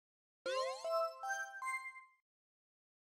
D.E.B.S. alert sound effect from Super Mario Sunshine
Edited Recreated from audio samples Image help • Image use policy • Media file guidelines Licensing [ edit ] Fair use sound clip This is a sound clip from a copyrighted work.
SMS_DEBS_Alert_sound_effect.mp3